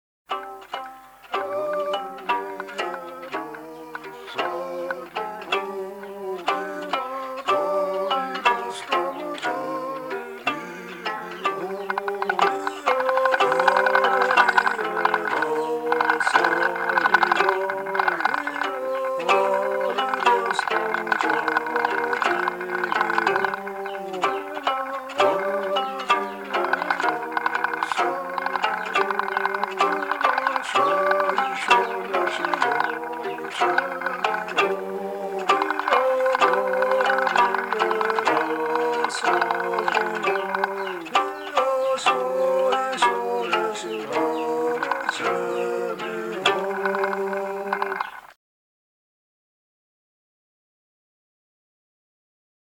ჩანგით)გადმოწერანახვა